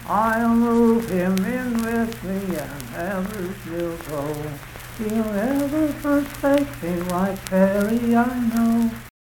Unaccompanied vocal music
Verse-refrain 5(2).
Voice (sung)